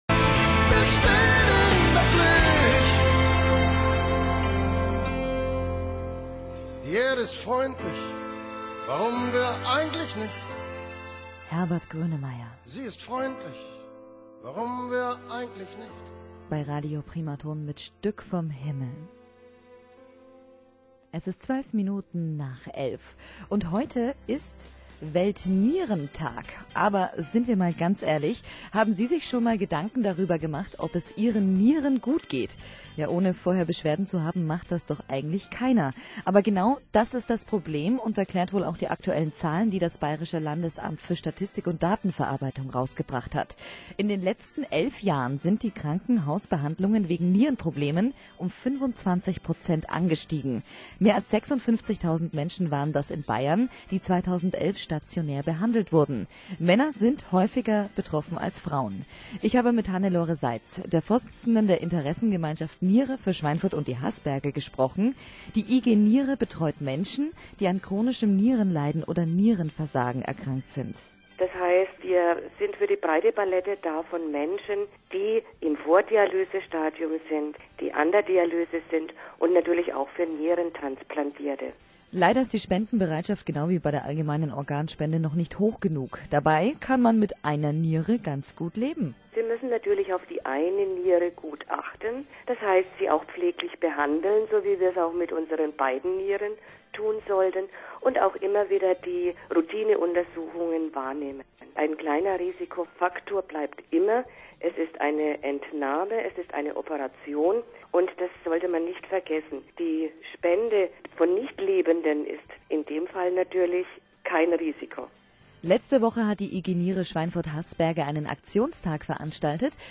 Interview zum Weltnierentag
13_03_Weltnierentag_Primaton.wma